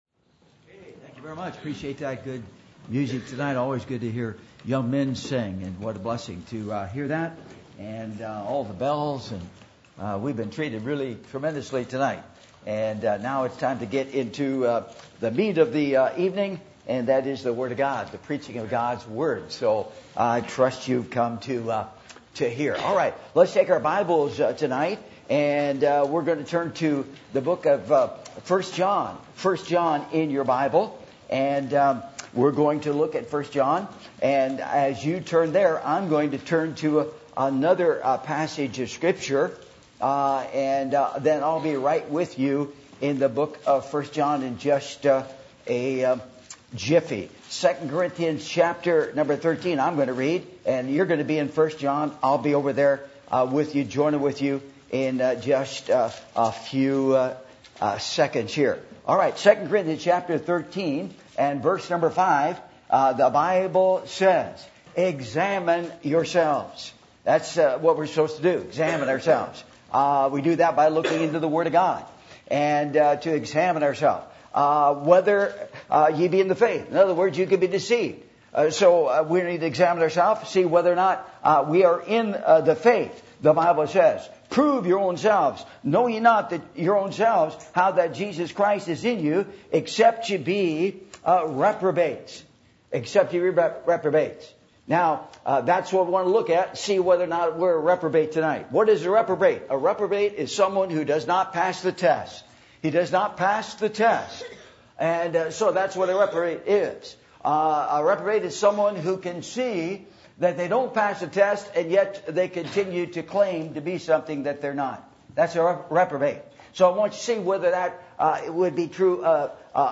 Passage: 2 Corinthians 13:5-6 Service Type: Revival Meetings %todo_render% « Are You Growing Spiritually?